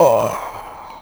c_zombim3_hit1.wav